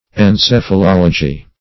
Meaning of encephalology. encephalology synonyms, pronunciation, spelling and more from Free Dictionary.
Search Result for " encephalology" : The Collaborative International Dictionary of English v.0.48: Encephalology \En*ceph`a*lol"o*gy\, n. [Gr.